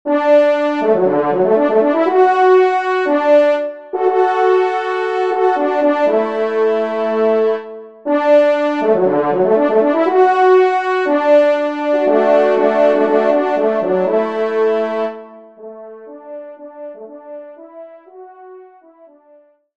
Genre :  Divertissement pour quatre Trompes ou Cors
Pupitre 2°Trompe / Cor